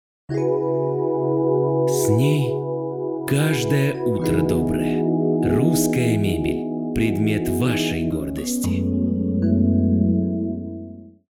Тракт: AKG P-120, Behringer u-phoria umc22
Демо-запись №1 Скачать